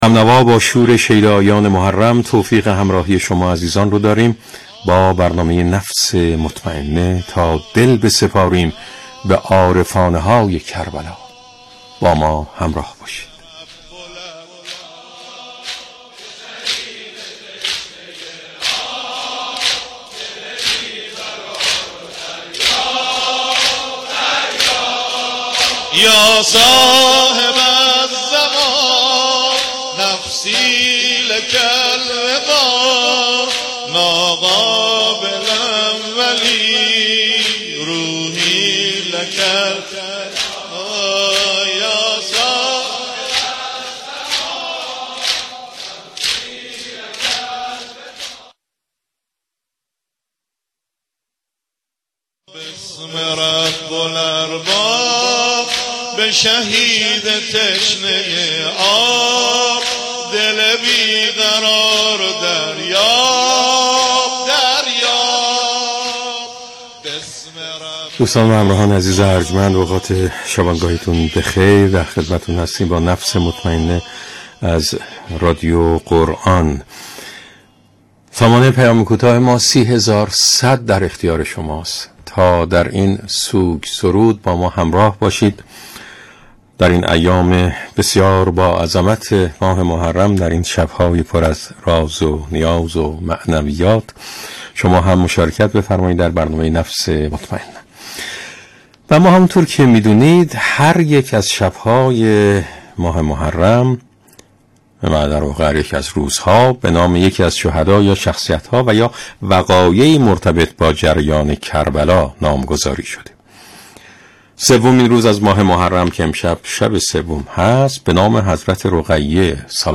یکی از ویژه‌برنامه‌های محرمی رادیو قرآن « نفس مطمئنه» است که به صورت زنده طی 12 شب نخست ماه سوگواری امام حسین(ع) به روی آنتن می‌رود.
به گزارش ایکنا، ویژه‌برنامه «نفس مطمئنه» از جمله برنامه‌های سوگواری اباعبدالله الحسین(ع) است که در قالب هیئتی در استودیوی رادیو قرآن از شب اول محرم به مدت ۱۲ شب، ساعت ۲۱ به صورت زنده تقدیم شنوندگان می‌شود.